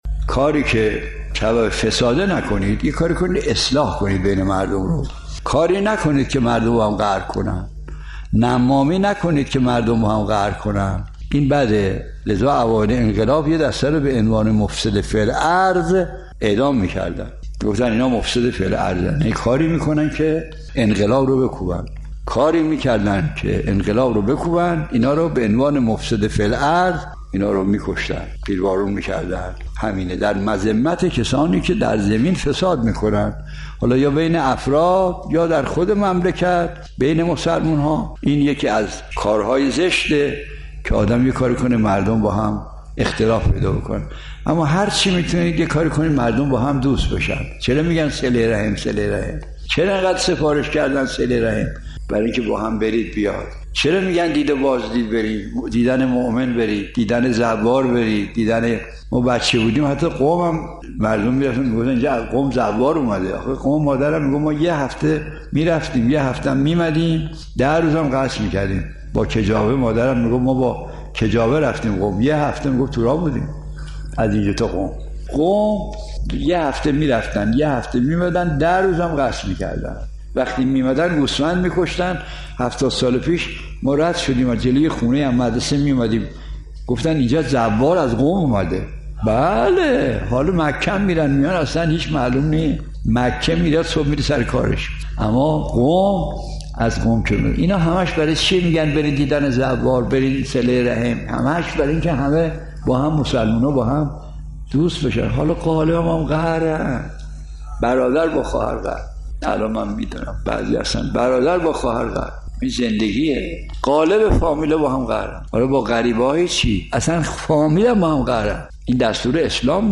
مرحوم آیت‌الله مجتهدی تهرانی، استاد اخلاق در یکی از سخنرانی‌های بر ضرورت ایجاد اصلاح بین مردم تأکید و فلسفه سفارش به صله رحم و دید و بازدید را در همین مسئله عنوان کرد.